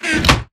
chestclosed.ogg